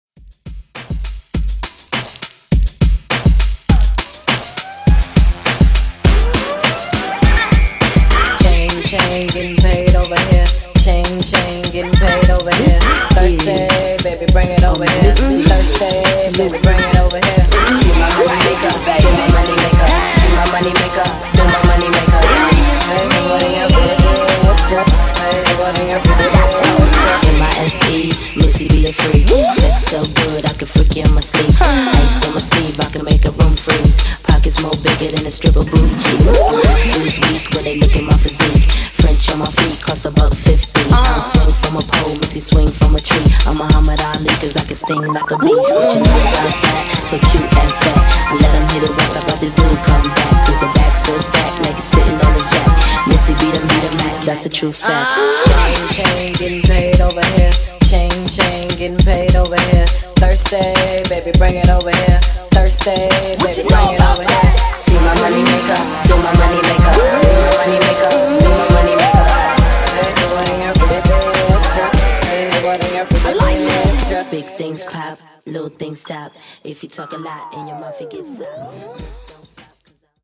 Old School Redrum)Date Added